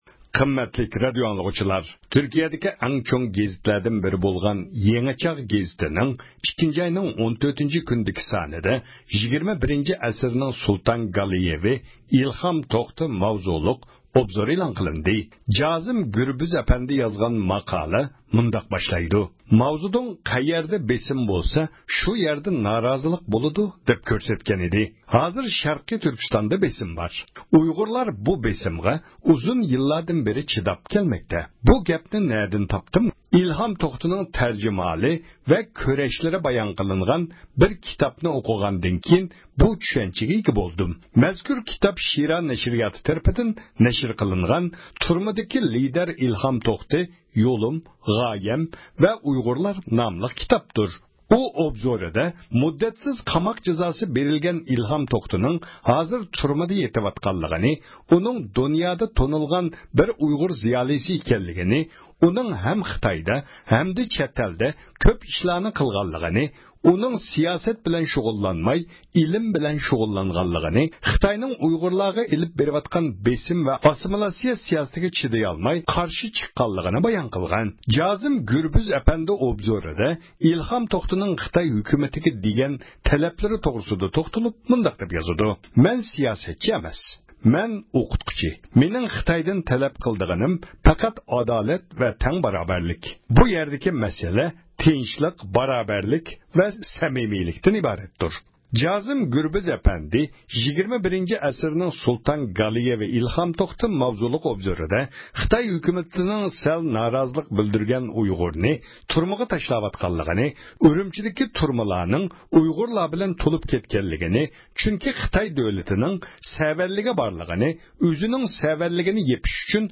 مەنبە: ئەركىن ئاسىيا ردىئوسى